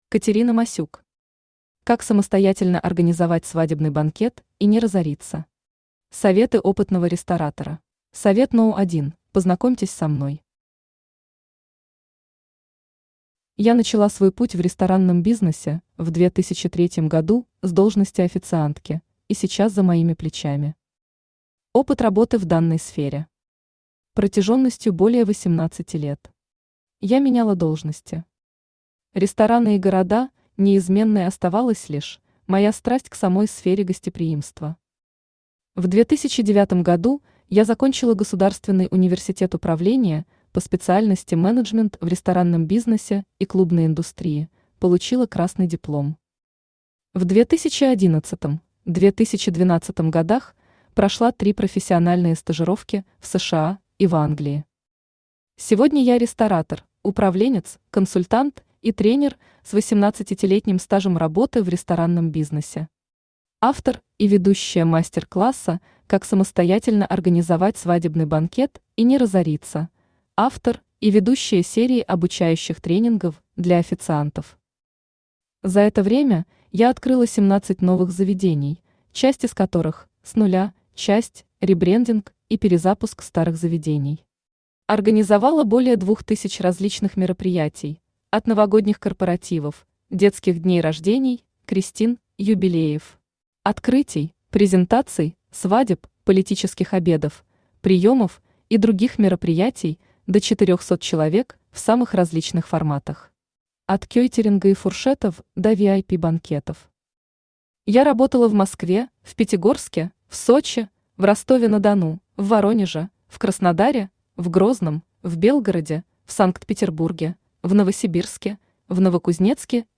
Аудиокнига Как самостоятельно организовать свадебный банкет и не разориться. Советы опытного ресторатора | Библиотека аудиокниг
Советы опытного ресторатора Автор Катерина Масюк Читает аудиокнигу Авточтец ЛитРес.